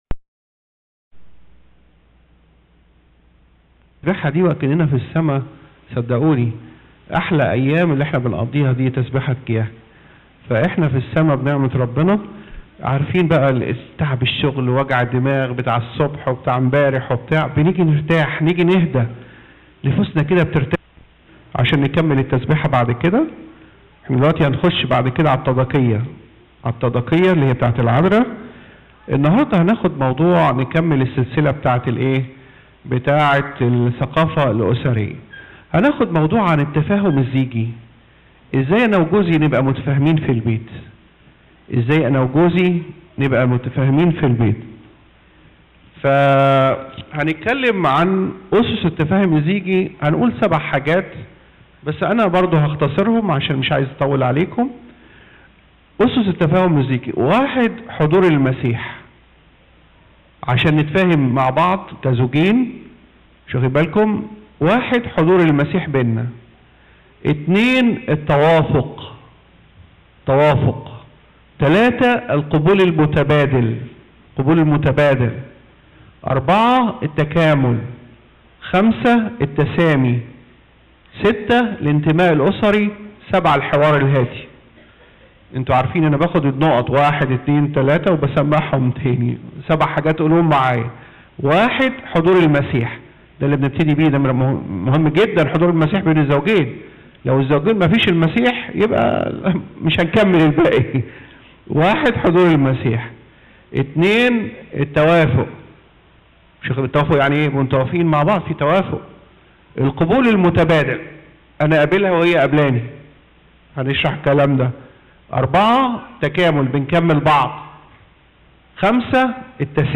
الموقع الرسمي لخدمة الشباب بناشفيل - صوتيات ومرئيات - عظات خدمة الشباب - كورس الثقافة الاسرية